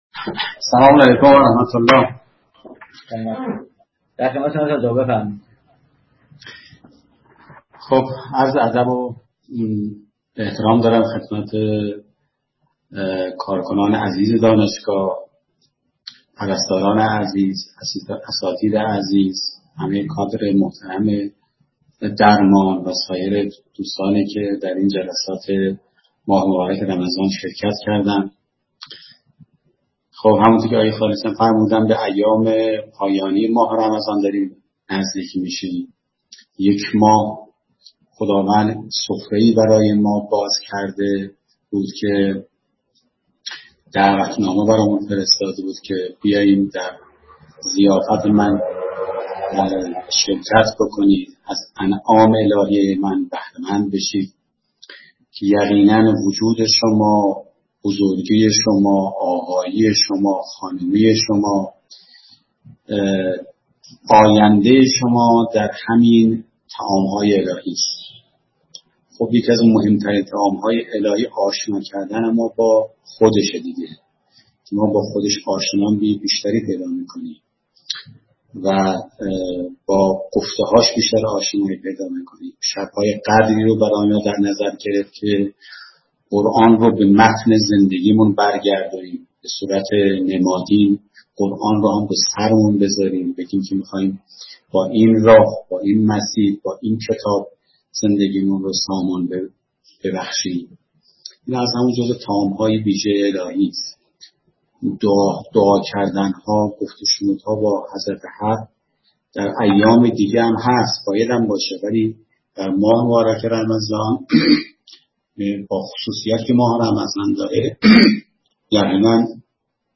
به همت مرکز فرهنگی قرآن و عترت نهاد نمایندگی مقام معظم رهبری، چهارمین جلسه از سلسله مباحث معرفتی قرآن به مناسبت ماه مبارک رمضان، روز سه‌شنبه 7 اردیبهشت در سامانه اسکای‌روم دفتر نهاد برگزار شد.